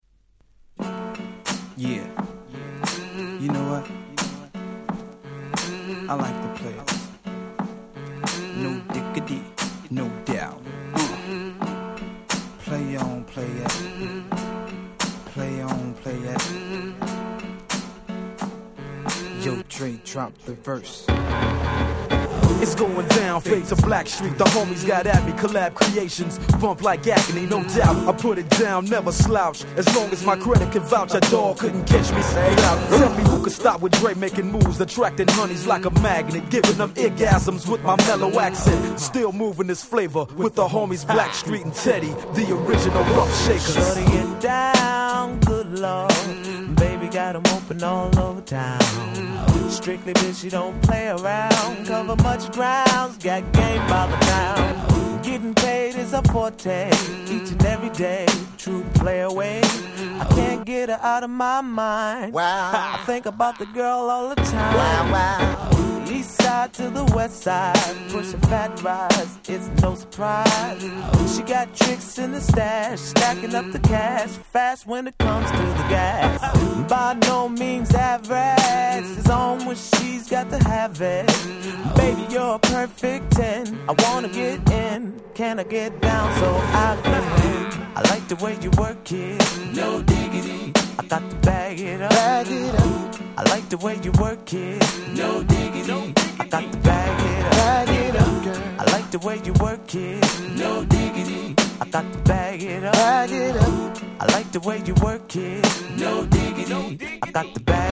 96' Super Hit R&B !!!